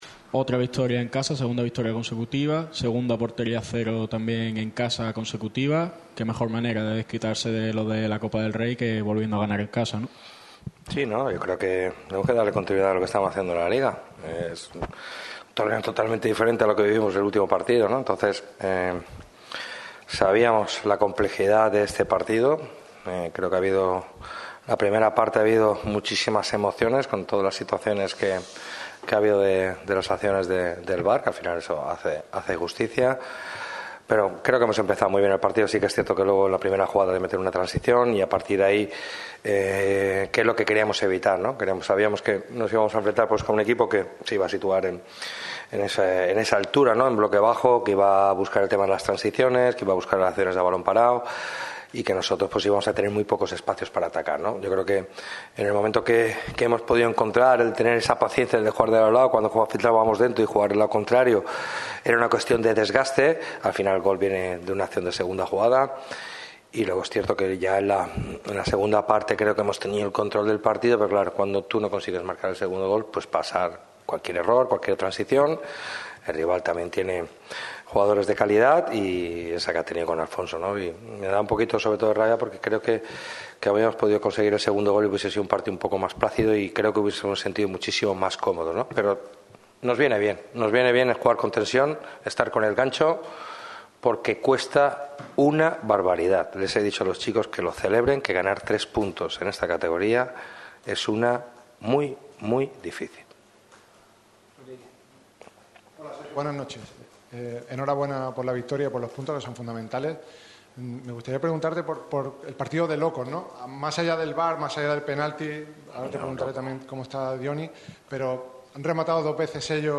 ha comparecido en rueda de prensa ante los medios tras la importante victoria en casa ante el FC Cartagena.